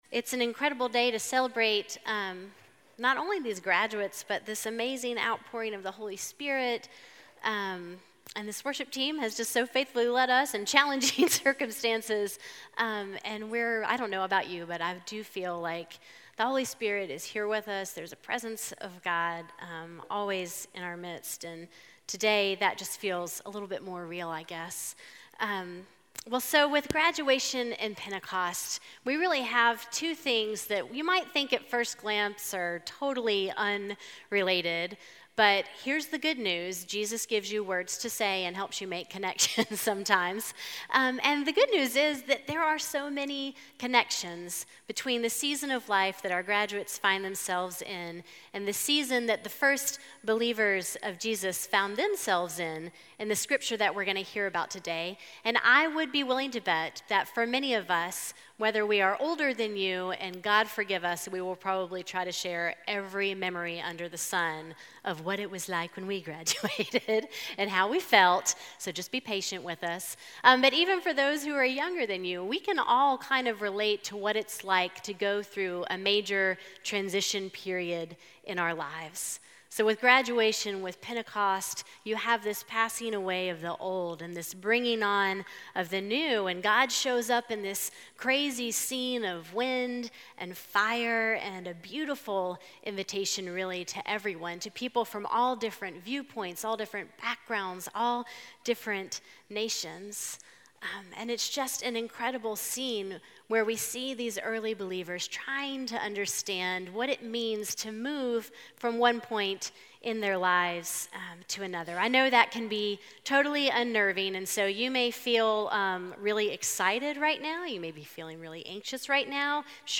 A message from the series "Uncategorized."